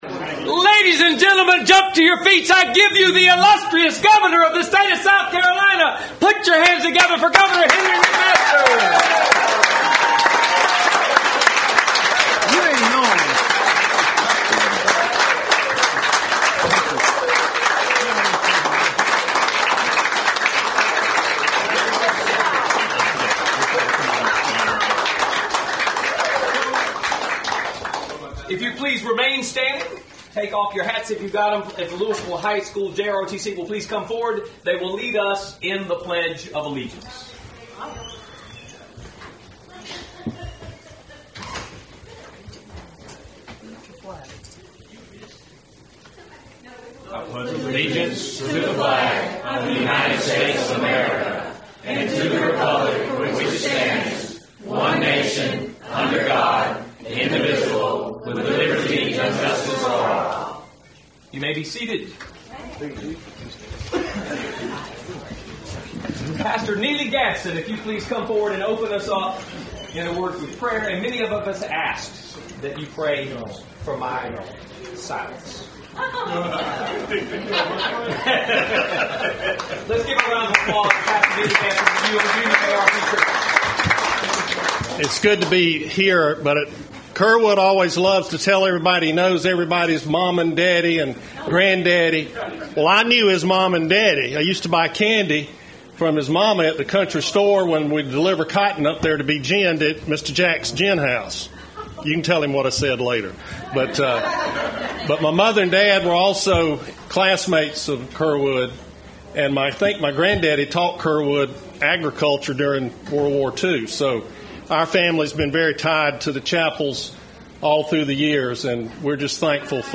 This is the audio of that ceremony.